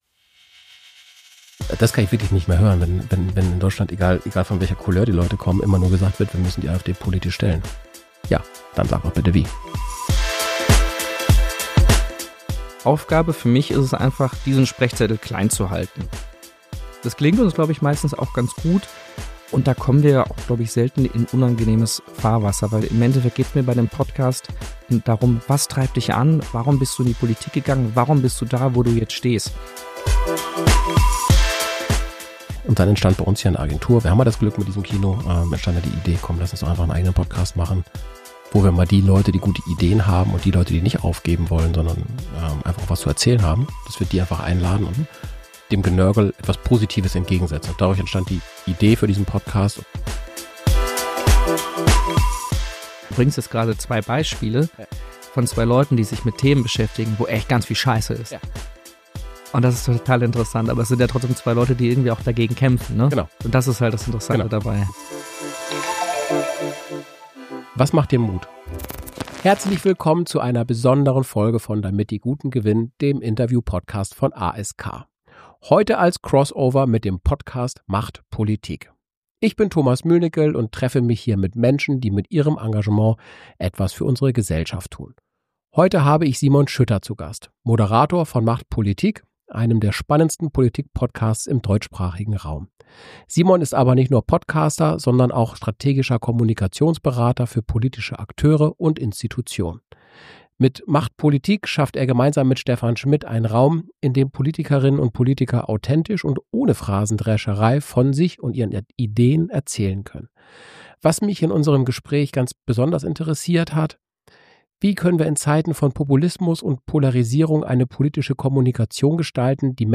Beschreibung vor 6 Monaten  Eine Spezialfolge von „Damit die Guten gewinnen“ – im Crossover mit dem Podcast „macht politik“.